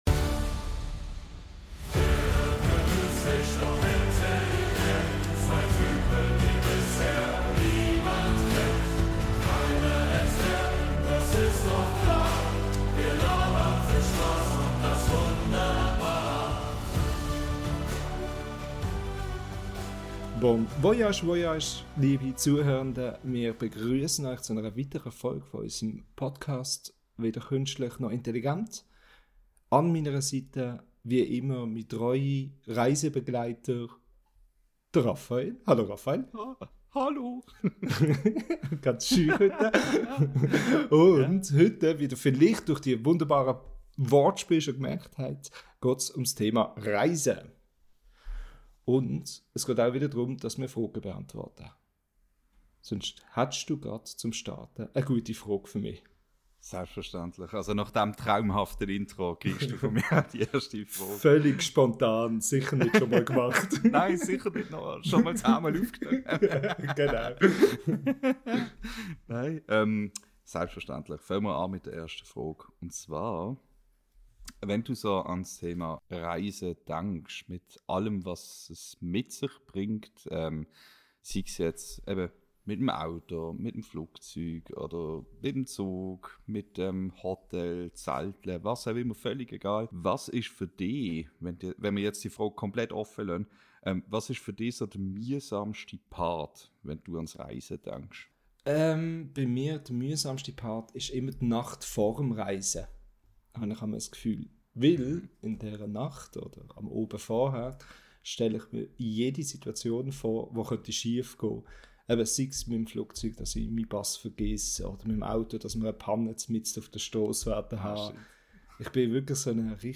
Beschreibung vor 1 Jahr Kommt mit uns in der dritten Folge unseres schweizerdeutschen Podcasts auf einen spannenden Audiotrip voller faszinierender Geschichten rund ums Thema Reisen.